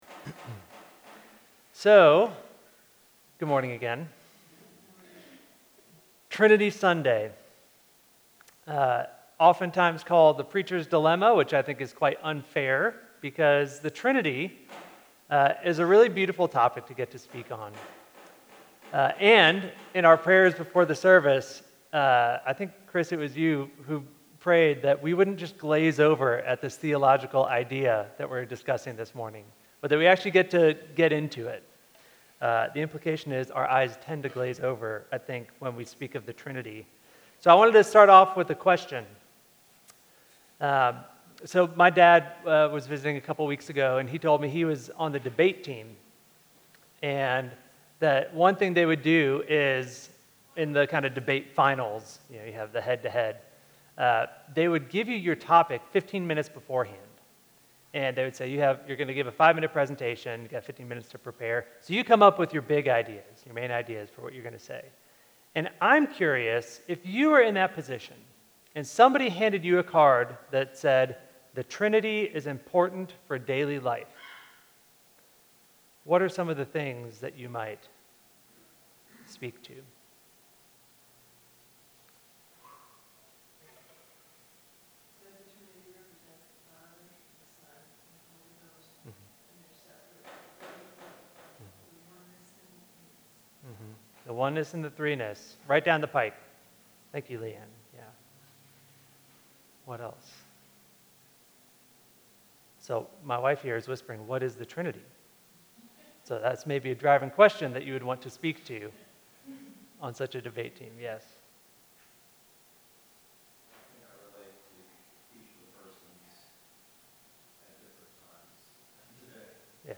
Trinity Sunday